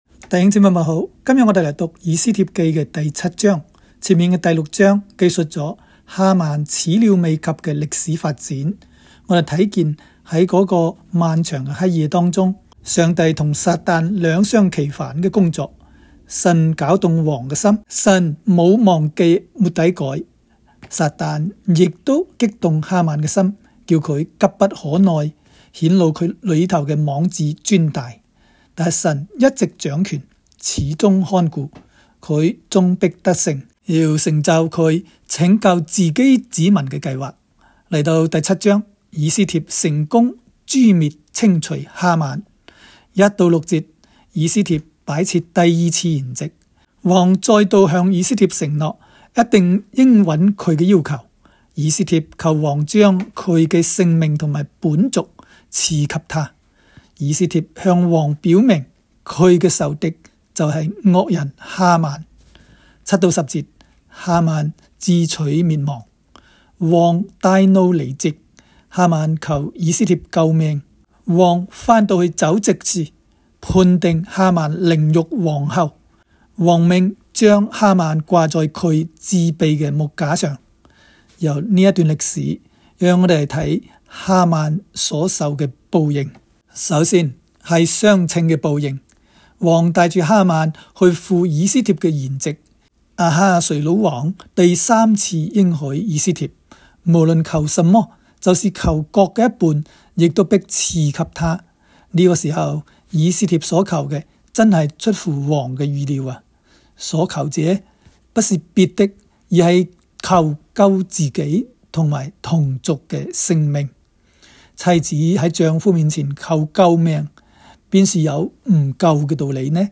斯07（讲解-粤）.m4a